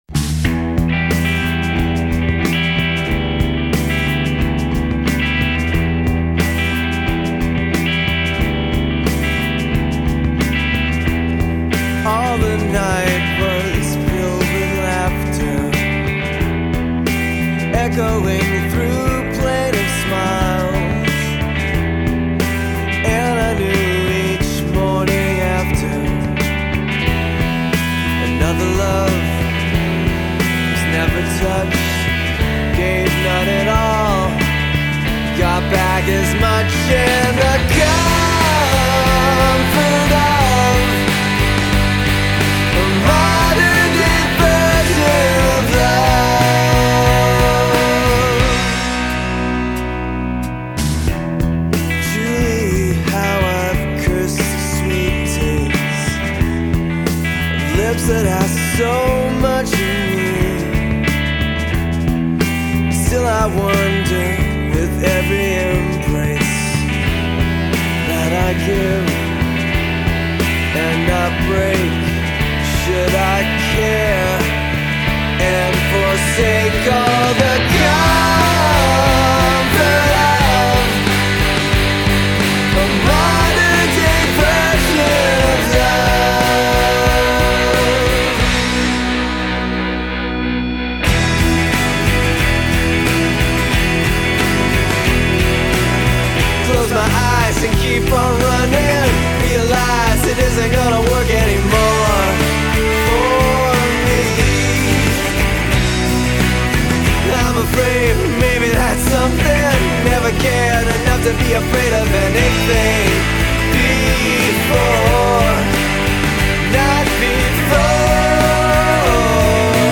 Recorded at Black Page Studio